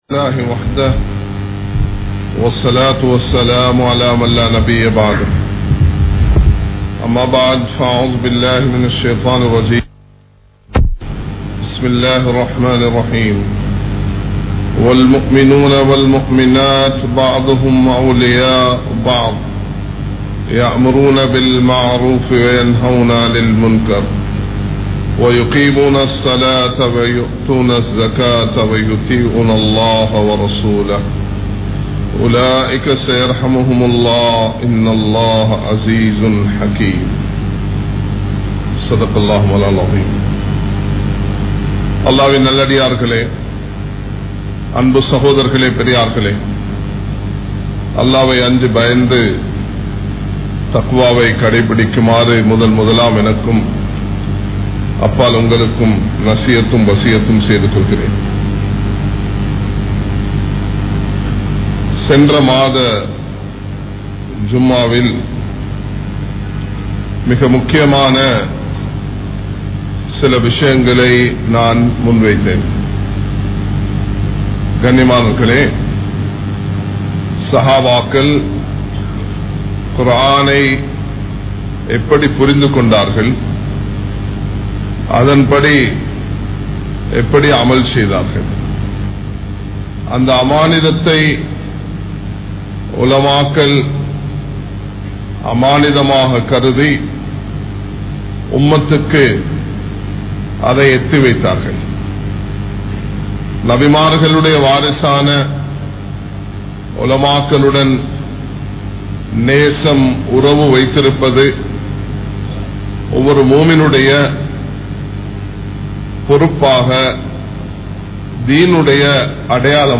Islaththin Valarchiel Pengalin Pangalippu (இஸ்லாத்தின் வளர்ச்சியில் பெண்களின் பங்களிப்பு) | Audio Bayans | All Ceylon Muslim Youth Community | Addalaichenai
Kollupitty Jumua Masjith